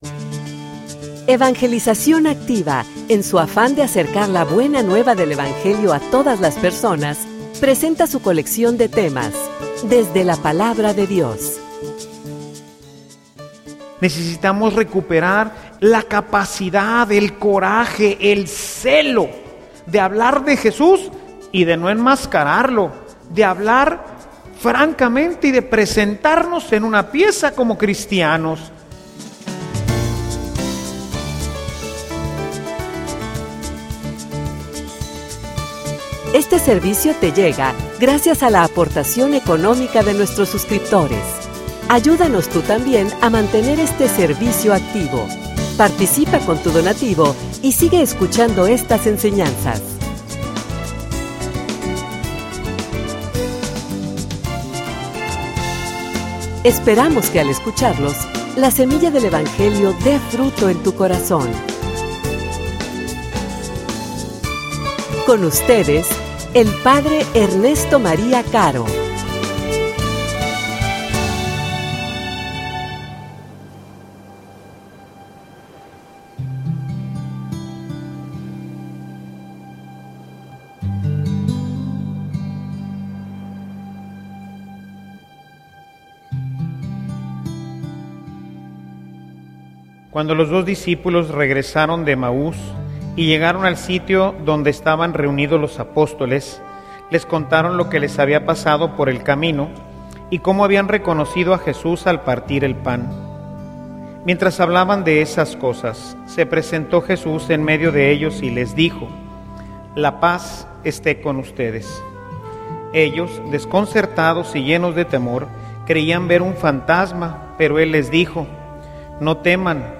homilia_Hablar_con_franqueza.mp3